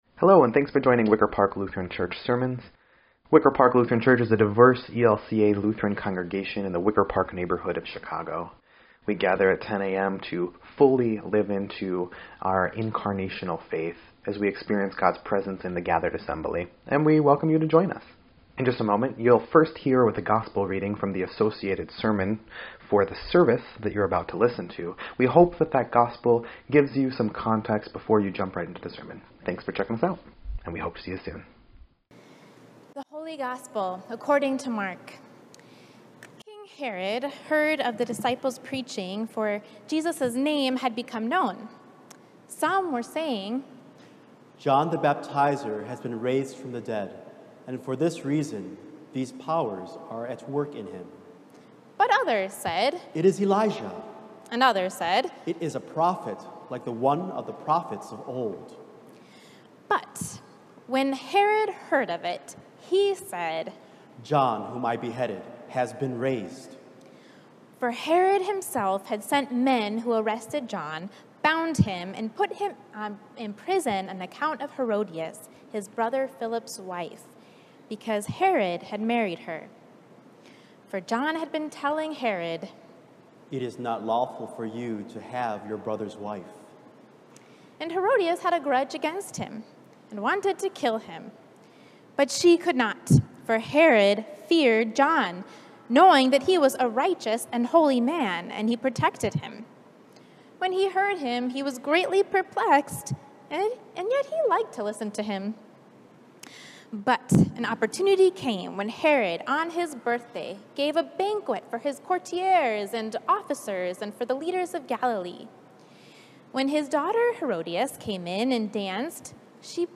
7.11.21-Sermon_EDIT.mp3